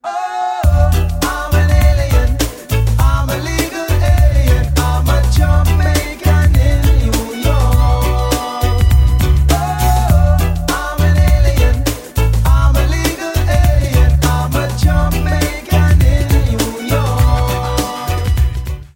• Качество: 128, Stereo
регги